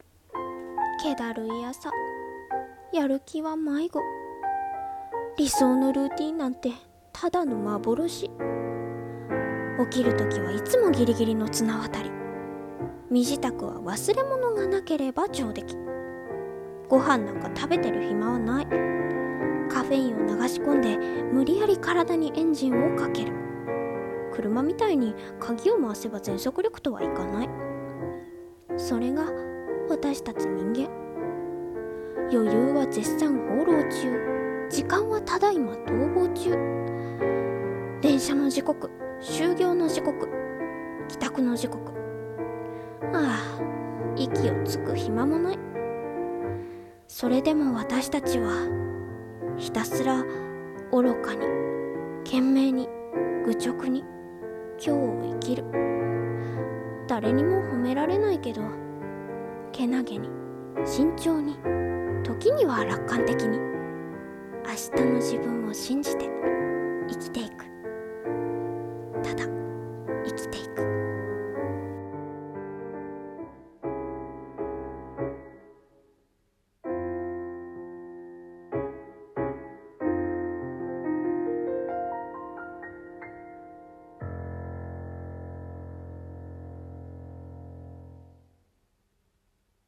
声劇台本『力を抜いて』一人用